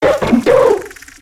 Cri de Miamiasme dans Pokémon X et Y.